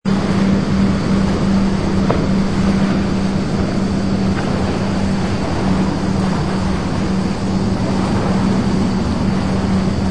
ambience_planetscape_water.wav